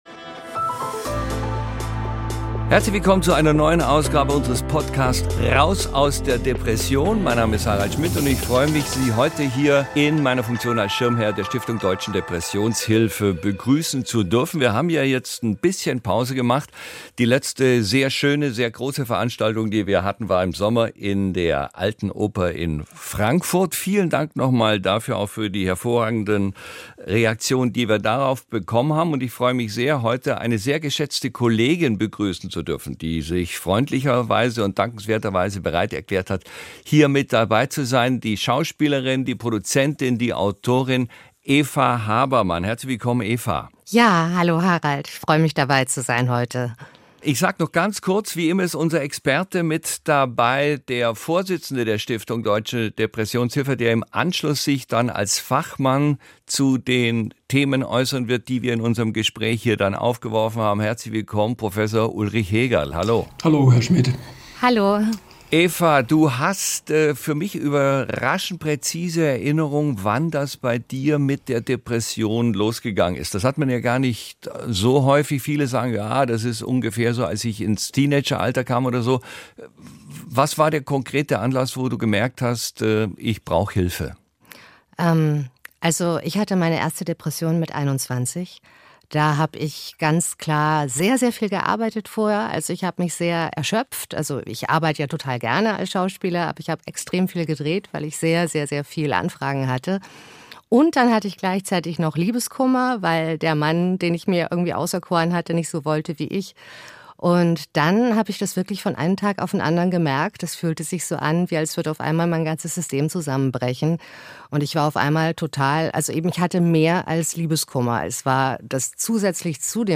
Harald Schmidt begrüßt in der dritten Staffel wieder seine Gäste als Schirmherr der deutschen Depressionshilfe und fragt nach, wie sie mit der Krankheit leben. Schauspielerin und Produzentin Eva Habermann hat die Stimulation des Gehirns mit starken Magnetimpulsen im Rahmen der Therapie geholfen. Außerdem erzählt Eva Habermann, welche Rolle Alkohol in ihrer Depression gespielt hat.